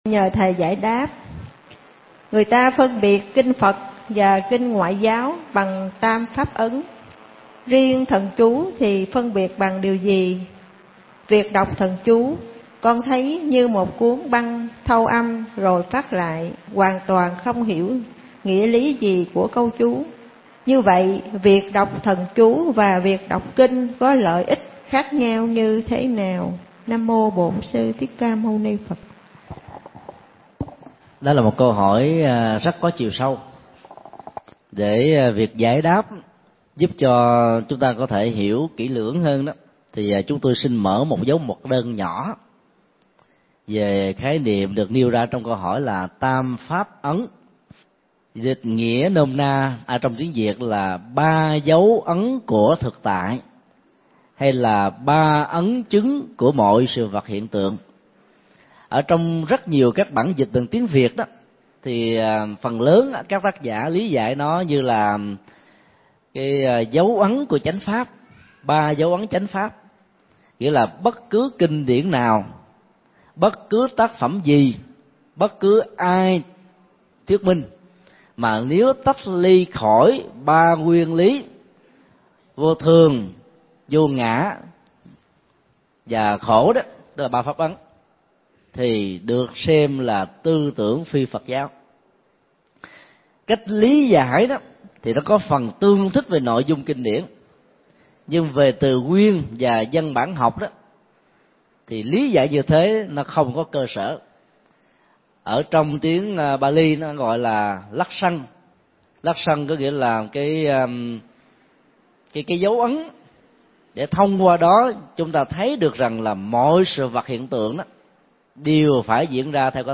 Nghe mp3 Vấn đáp: Tam Pháp Ấn, lợi ích đọc kinh- thần chú – Thầy Thích Nhật Từ